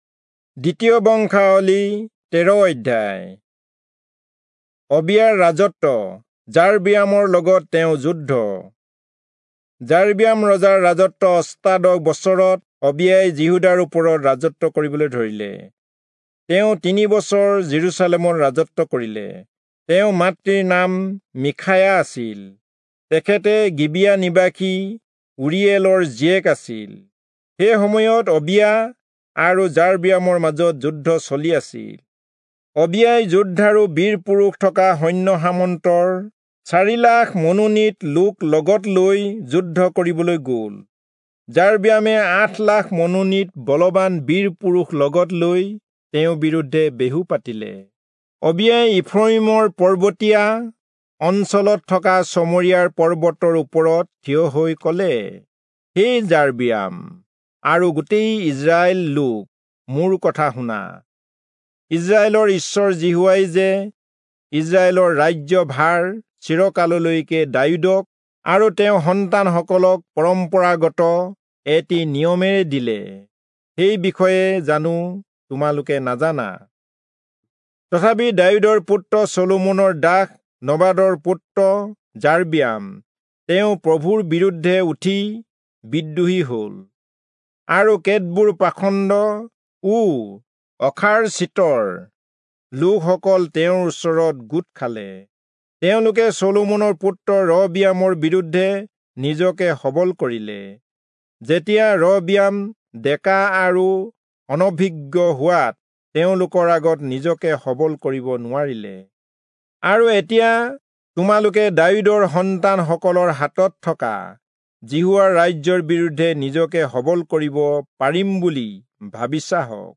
Assamese Audio Bible - 2-Chronicles 22 in Tov bible version